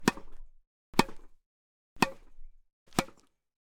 Quick Tennis Racket Hits OWI
fast hits impacts OWI playing quick racket shots sound effect free sound royalty free Sound Effects